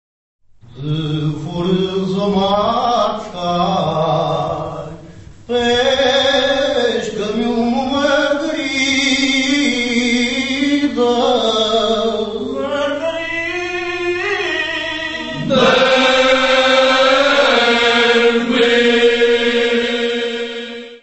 Series:  (Portuguese Folk Music; 4)
Music Category/Genre:  World and Traditional Music